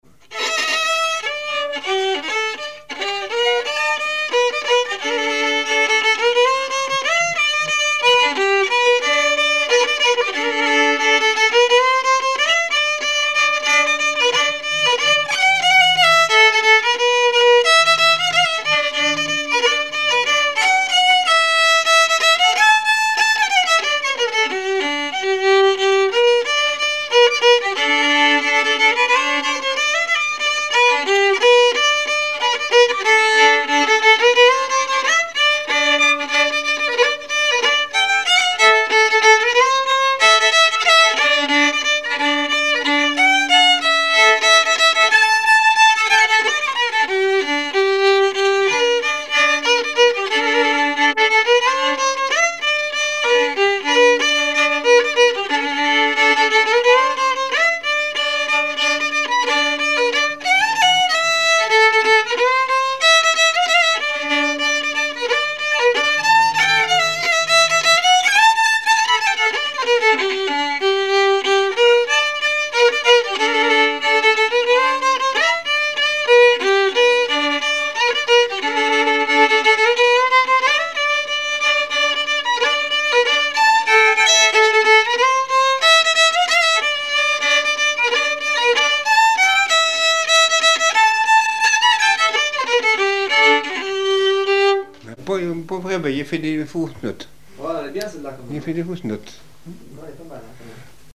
Chants brefs - A danser
danse : scottich trois pas
Activité du violoneux
Pièce musicale inédite